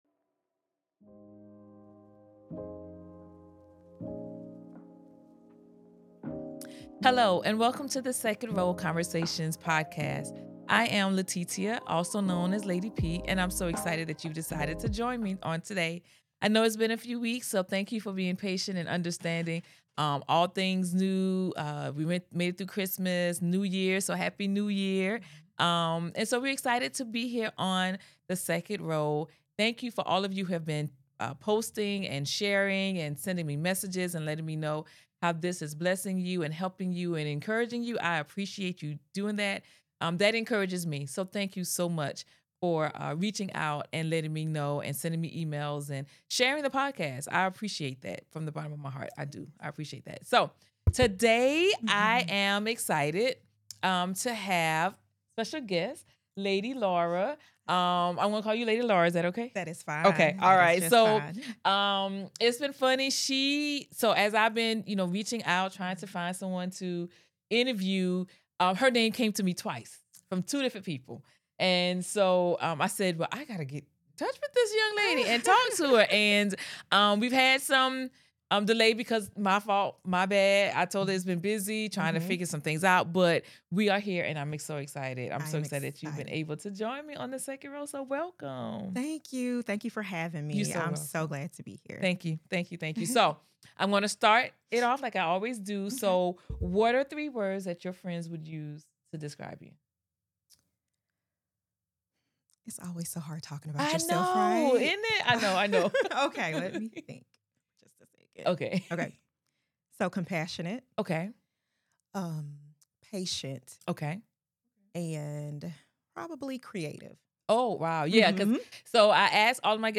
Join them for this inspiring conversation that will encourage you to refocus your heart, renew your mind, and set meaningful goals for the abundant life Christ promises.